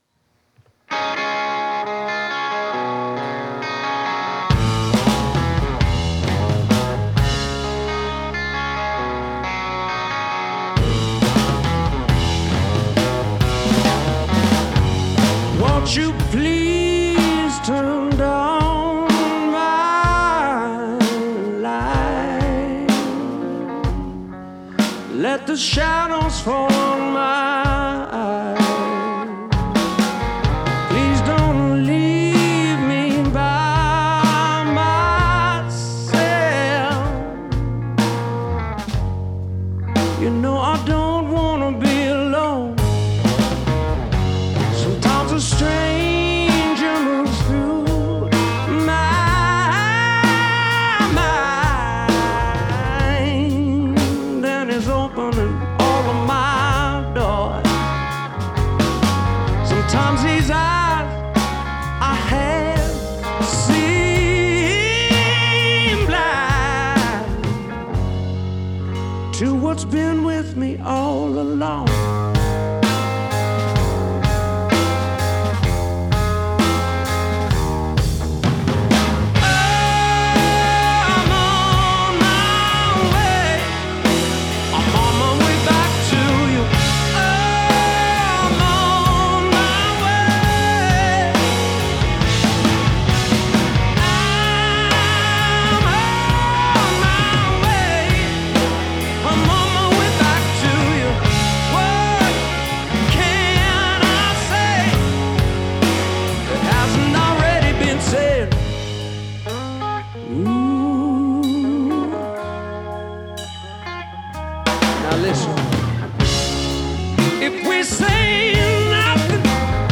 кто любит потяжелее